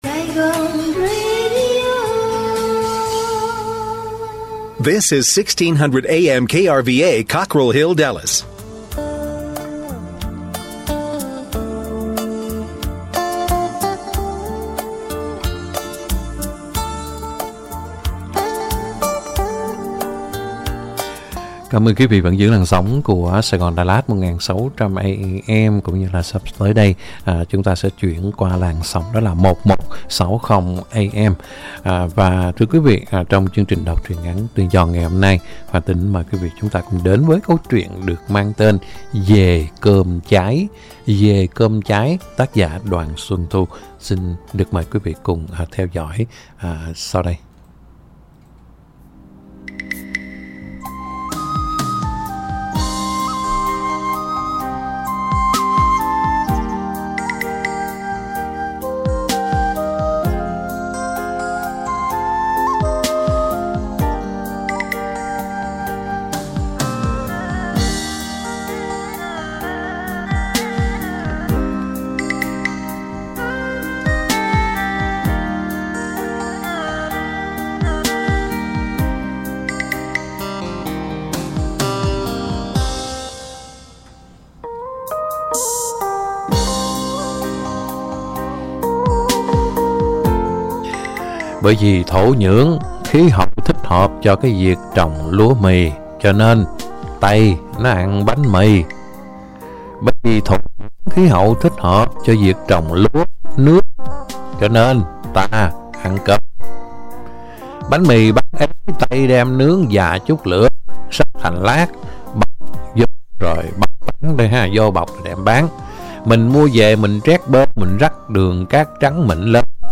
Đọc truyện ngắn = Dề cơm cháy!